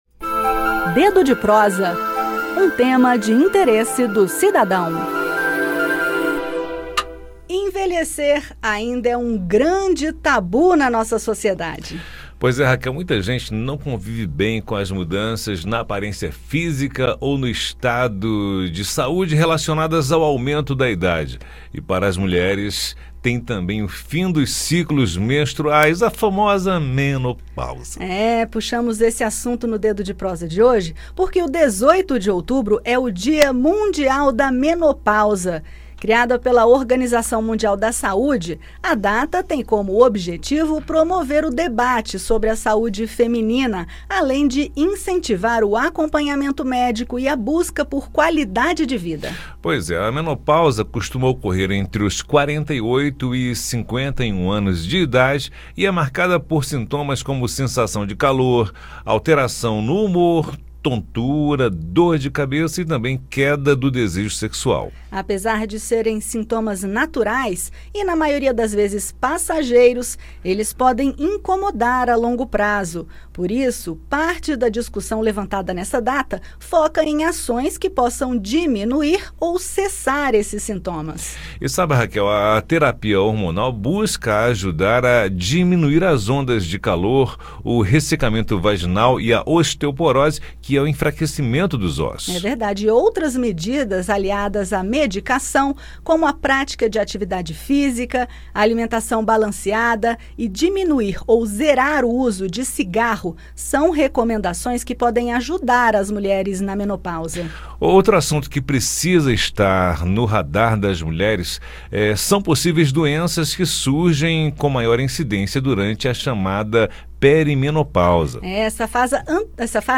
Com o objetivo de promover o debate sobre a saúde feminina e incentivar o acompanhamento médico e a busca por qualidade de vida, a Organização Mundial de Saúde criou o Dia Mundial da Menopausa, 18 de outubro. No bate-papo, entenda os desafios da menopausa, os sintomas e terapias e as orientações dos profissionais de saúde.